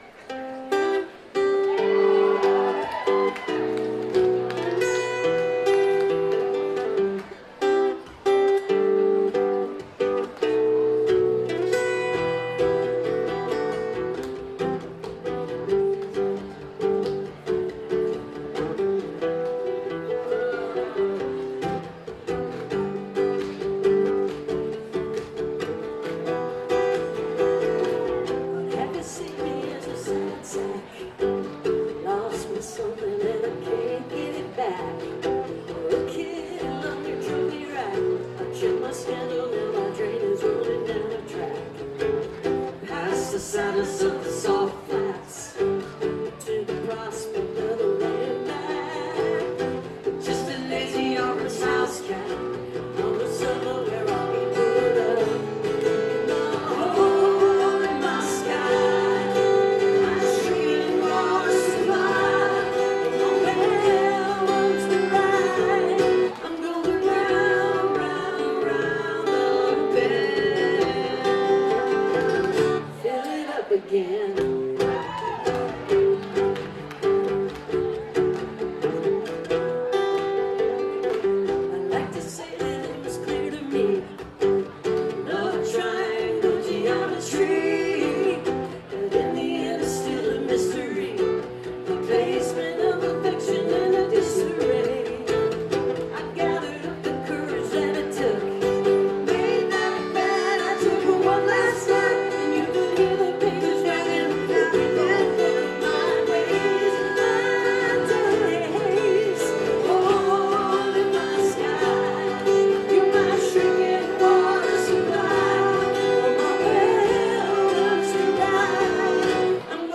live stream on twitch